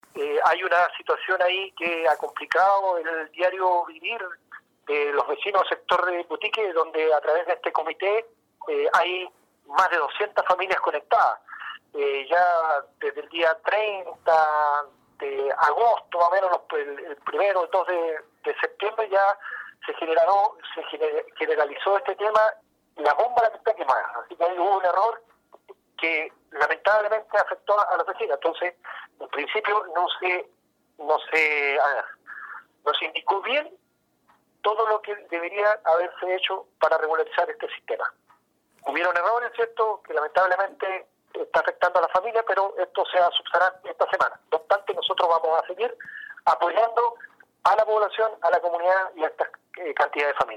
11-ALCALDE-QUINCHAO.mp3